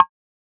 edm-perc-49.wav